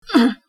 Weird Grunt Sound Button - Free Download & Play